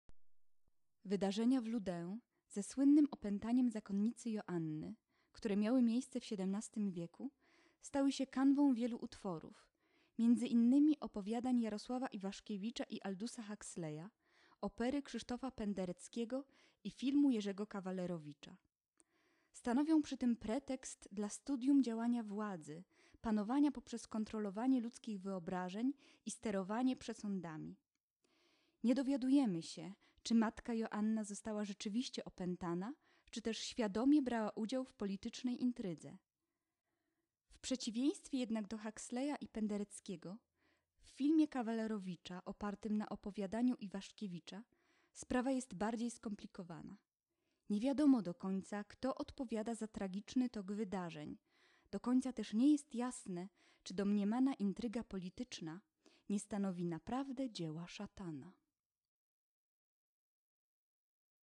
włącz  lektor